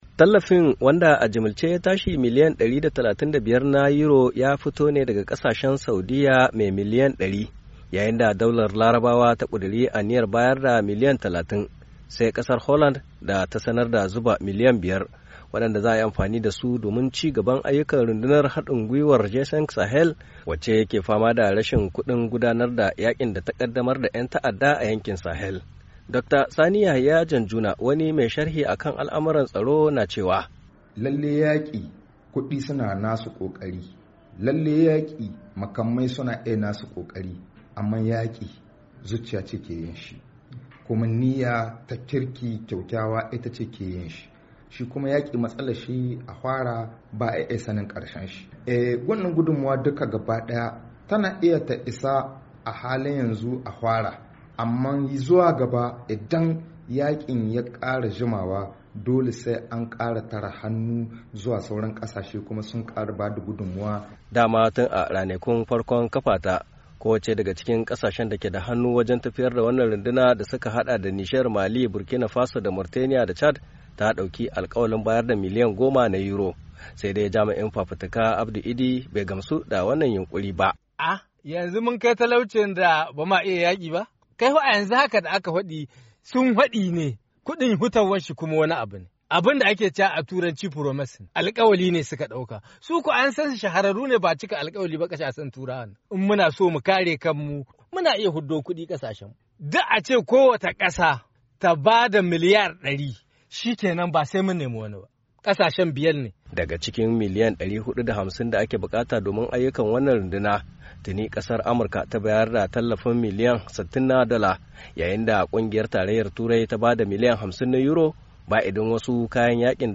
Ga karin bayani daga wakilin sashen hausa